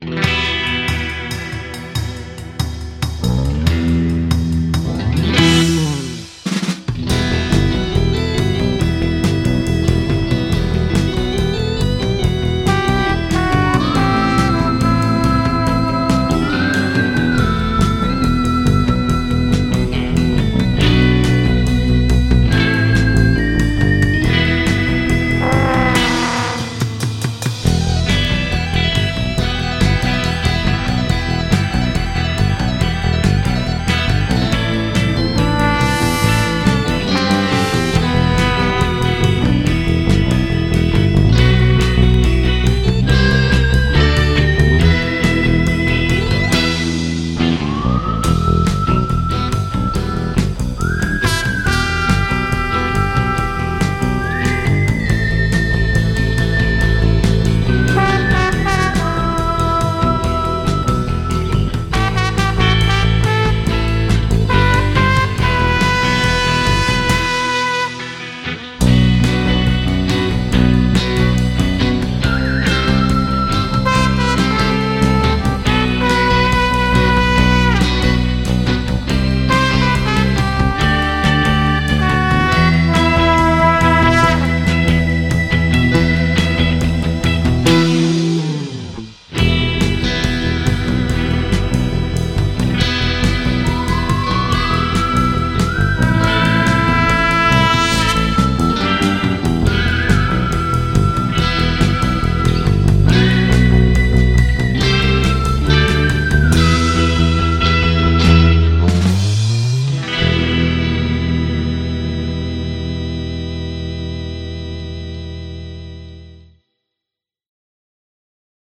Electronic Techno Sounds » revo robot effects male vocal
描述：electronic sounds effects for my electronics collection
标签： alternative elcectic electronic progressive punk techno
声道立体声